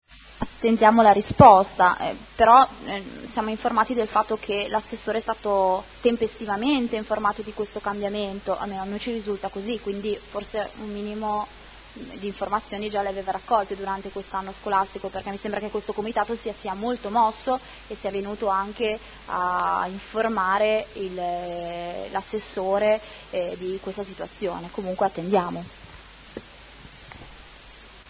Seduta del 1° ottobre. Interrogazione del Gruppo Consiliare Movimento 5 stelle avente per oggetto: Orario anno scolastico 2015/16 per l’Istituto Statale di 1° grado “Pasquale Paoli” (Sede Paoli – Sede San Carlo). Replica